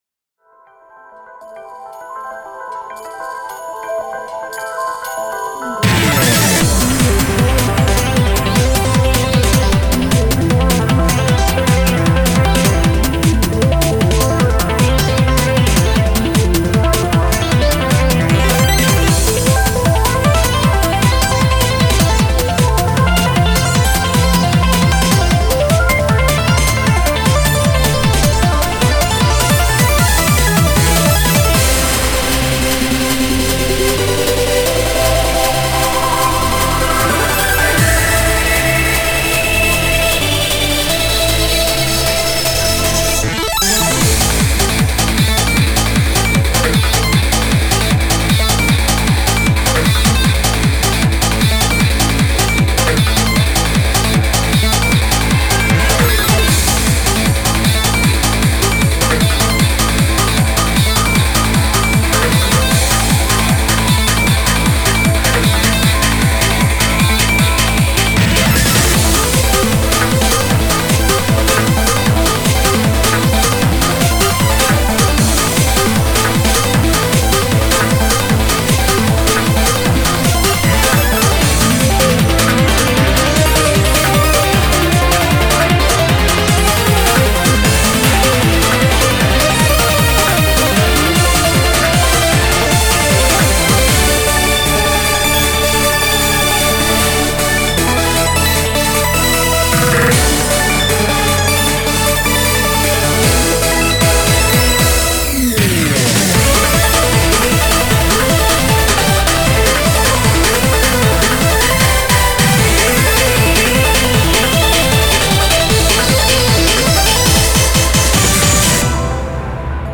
BPM154
Audio QualityPerfect (High Quality)
"Trendy Trance"